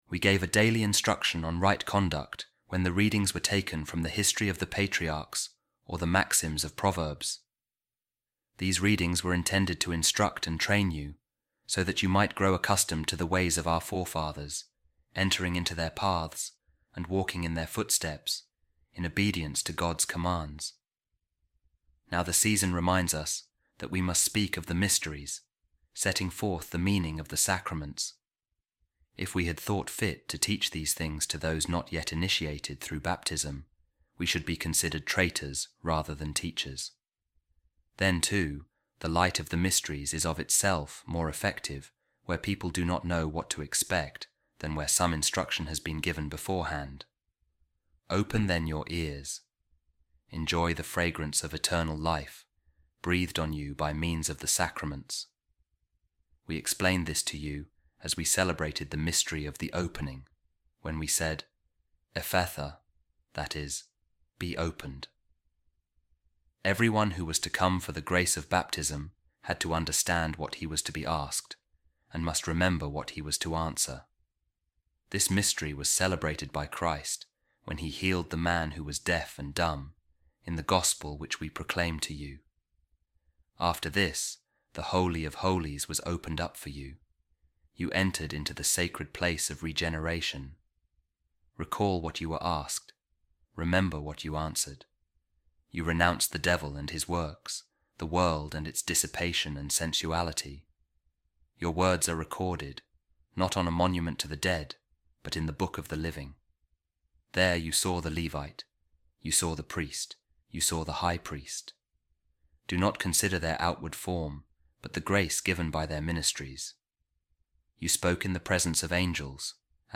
A Reading From The Treatise Of Saint Ambrose On The Mysteries | Instruction On The Ceremonies Preceding Baptism